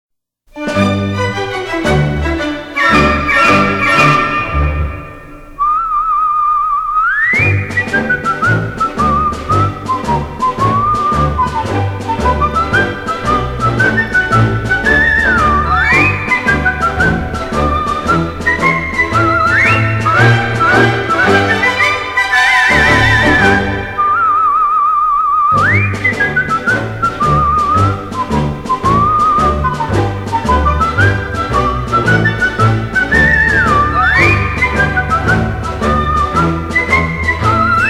【名曲轻音乐】
专辑语言：纯音乐
全曲欢快而富有跳跃感，很好玩~